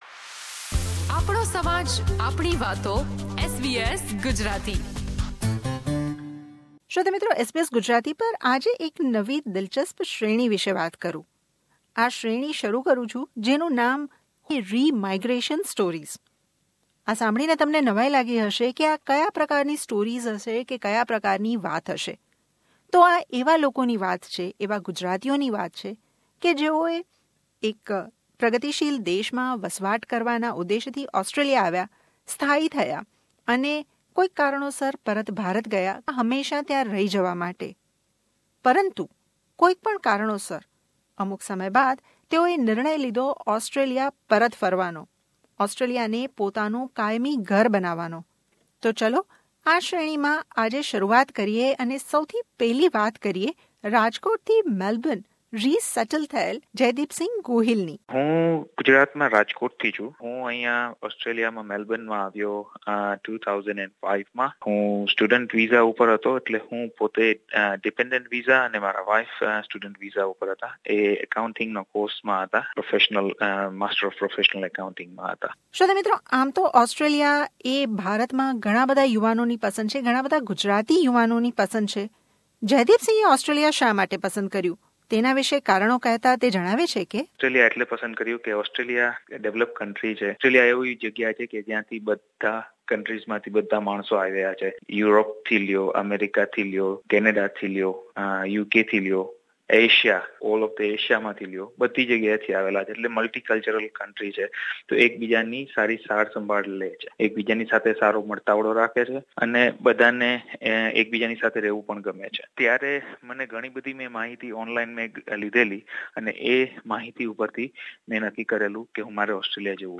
વાતચીત